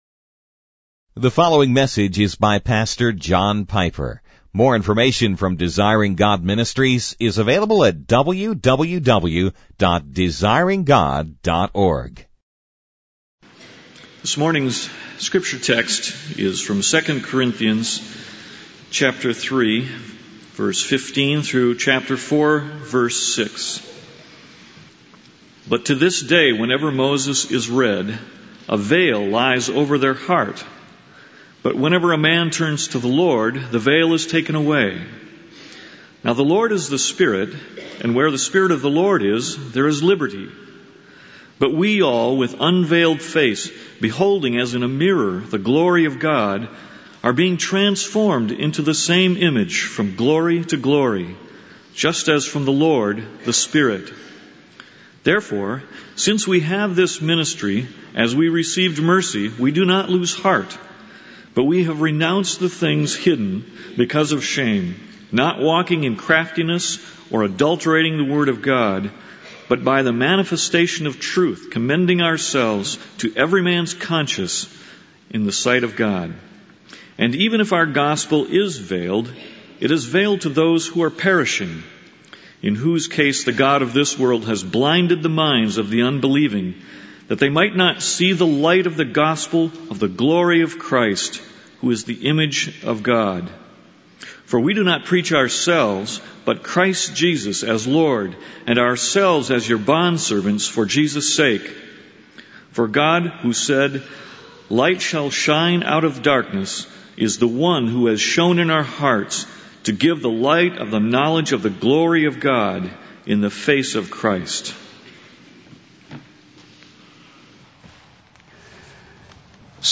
In this sermon, the speaker emphasizes the importance of remaining steadfast in one's faith in Jesus, even in the face of threats or challenges. The speaker encourages listeners to be evangelists and share the message of Jesus with others, highlighting the uniqueness and significance of Jesus.